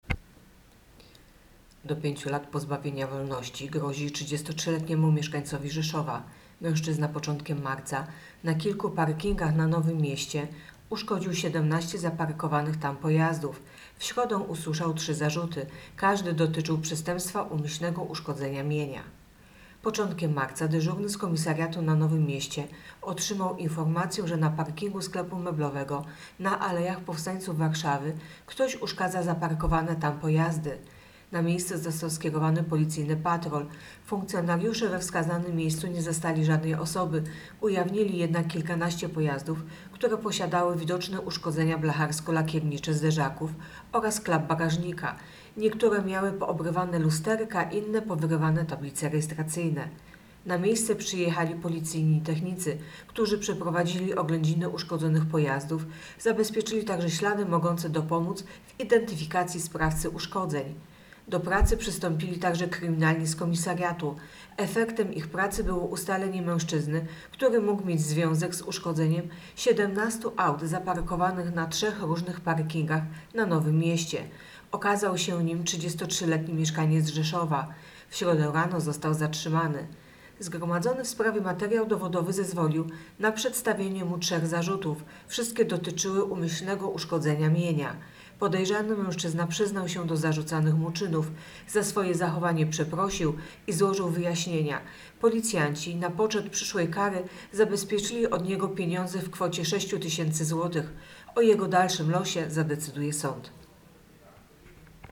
Opis nagrania: Nagranie informacji pt. 33-latek uszkodził 17 pojazdów - usłyszał zarzuty.